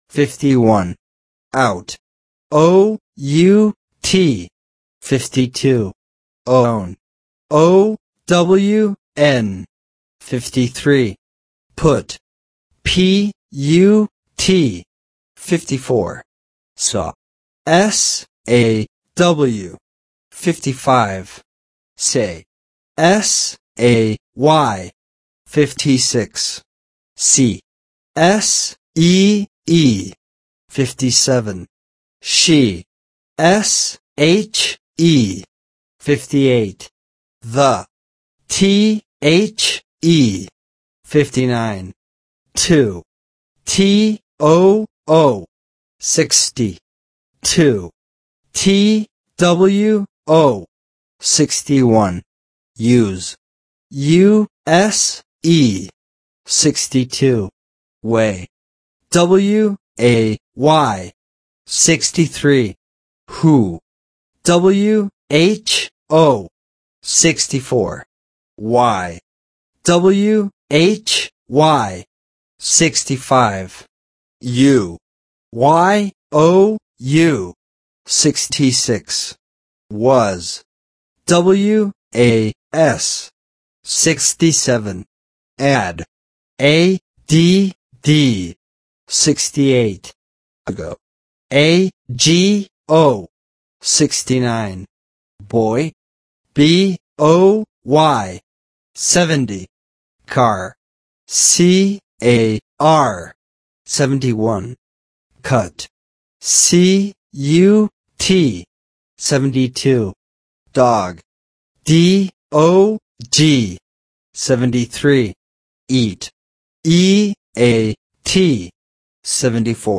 Spelling Exercises
51-100-spelling-words.mp3